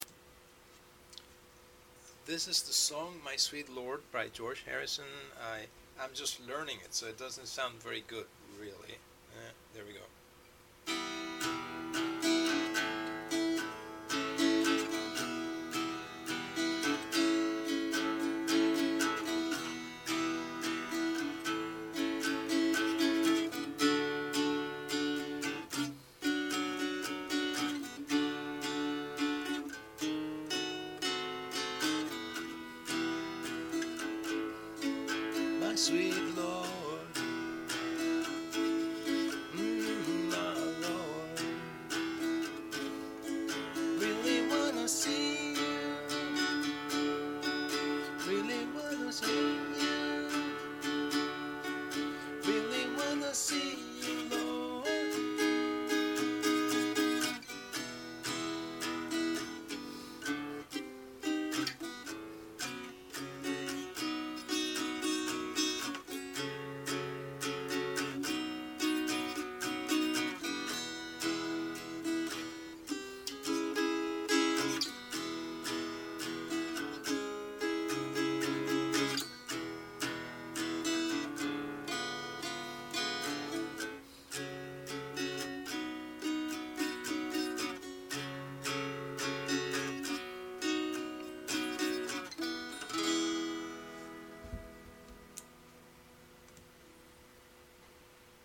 guitar beginner practice